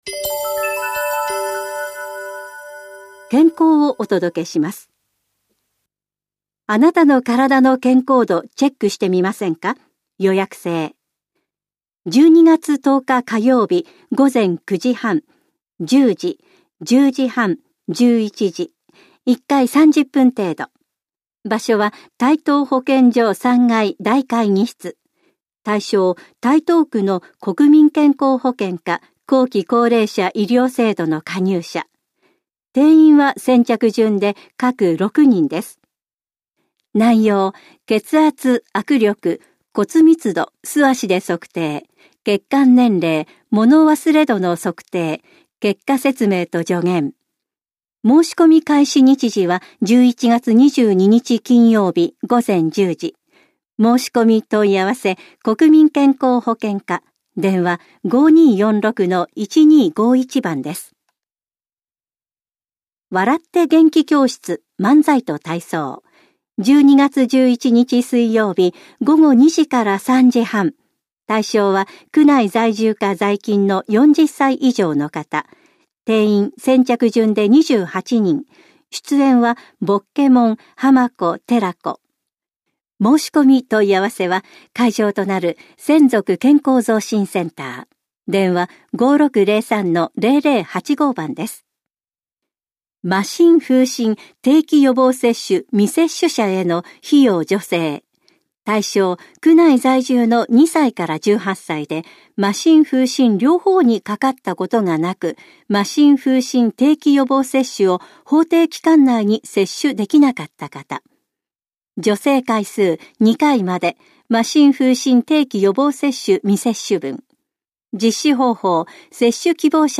広報「たいとう」令和元年11月5日号の音声読み上げデータです。